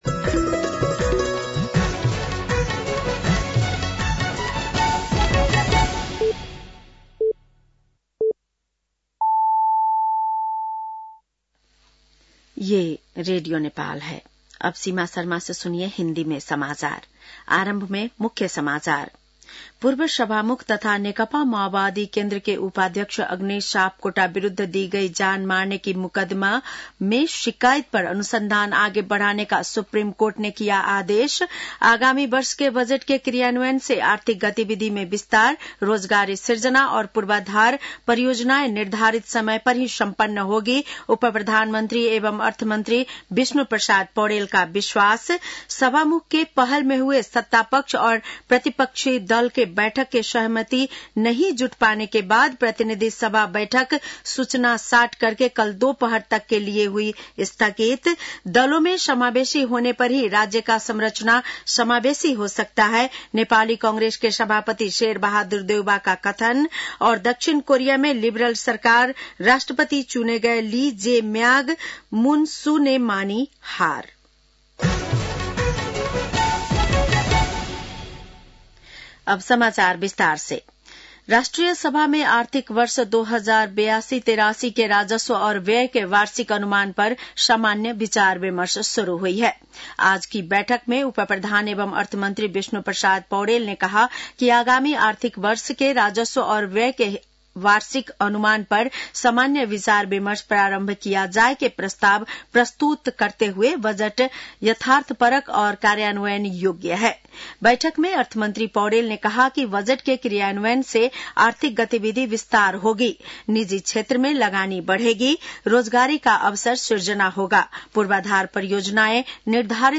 बेलुकी १० बजेको हिन्दी समाचार : २१ जेठ , २०८२